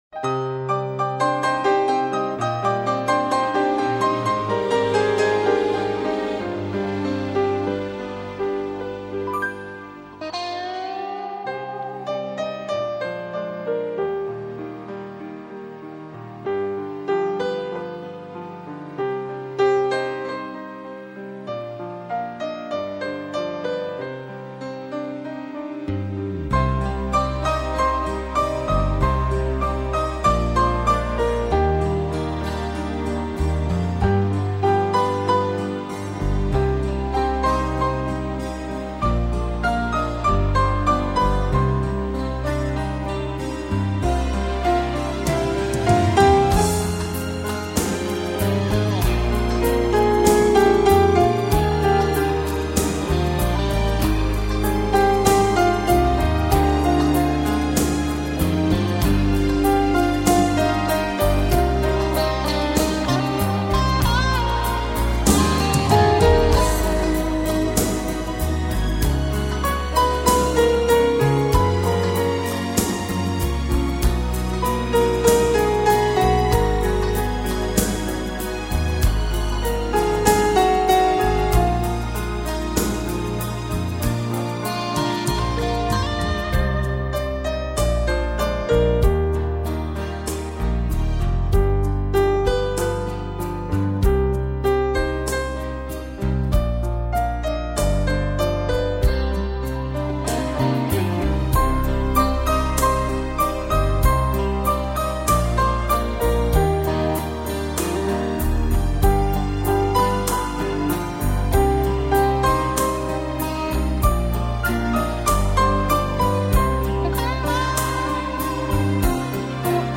Рояль с оркестром. Романтическая красивость.